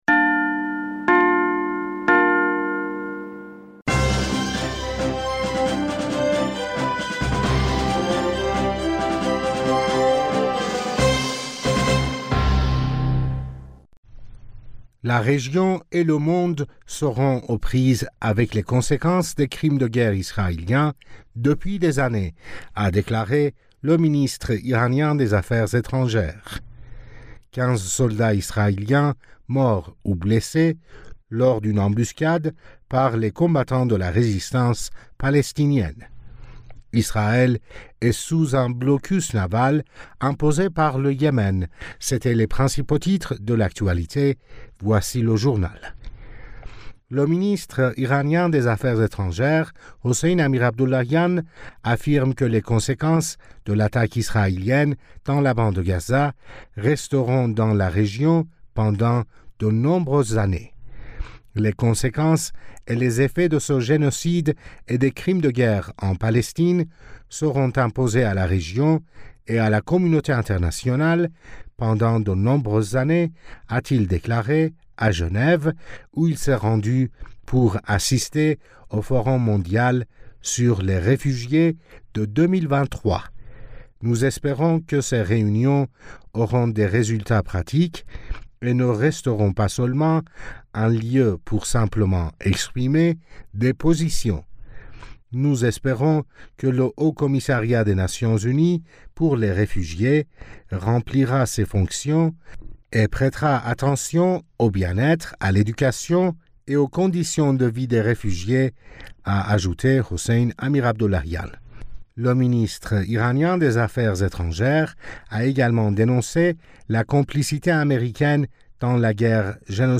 Bulletin d'information du 14 Decembre 2023